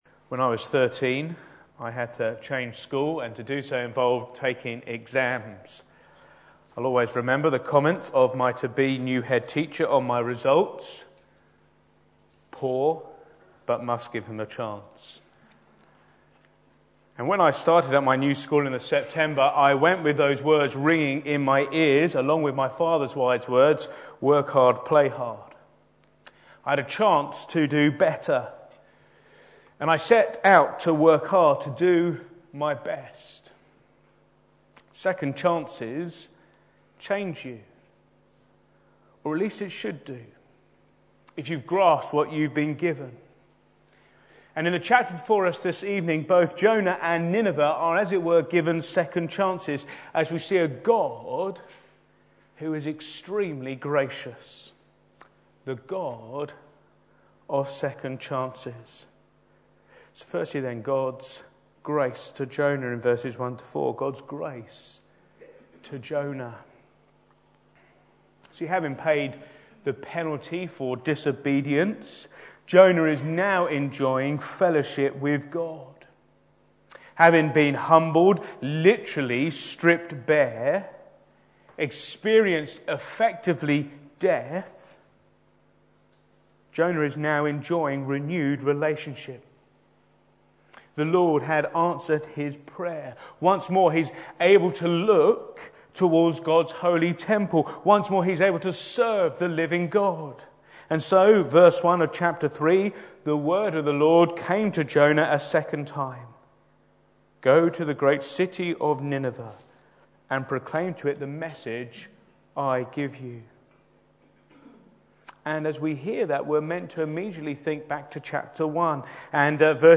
Back to Sermons Successful Preaching